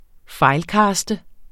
Udtale [ ˈfɑjlˌkɑːsdə ]